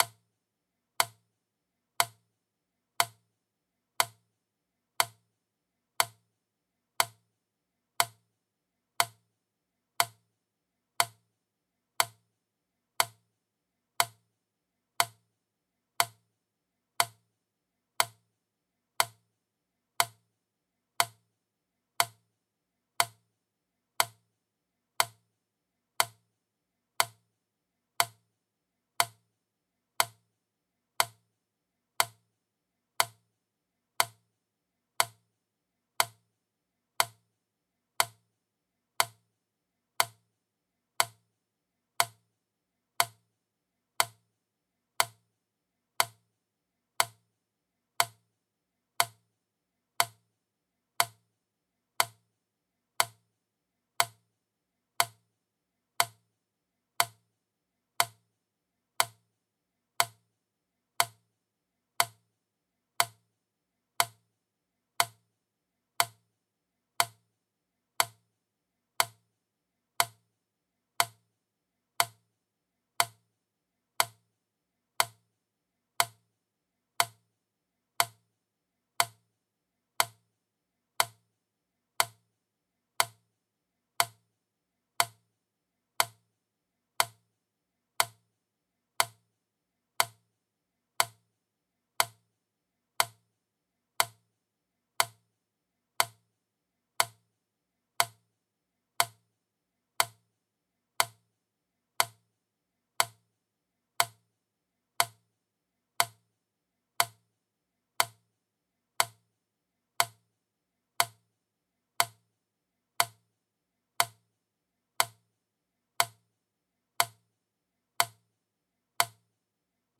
keep the metronome's ticking for freedom, free thought and democracy
30 minutes metronoom.mp3